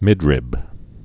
(mĭdrĭb)